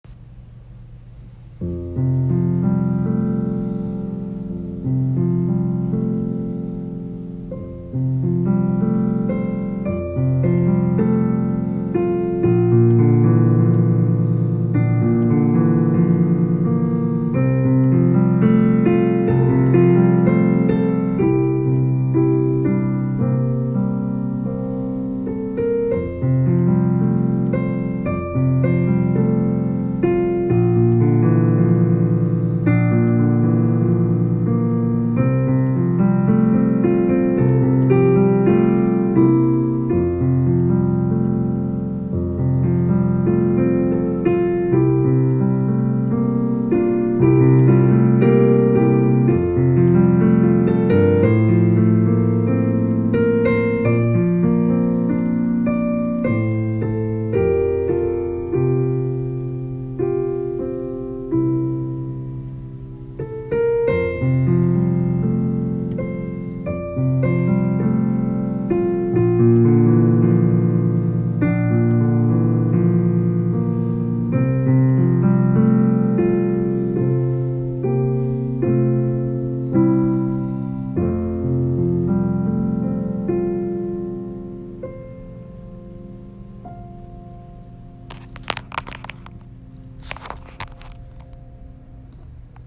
That picture transfers from my brain to my hands and the piano keys.
So, I sat down at the piano and began to play.
After playing it in several different ways, I decided to record myself.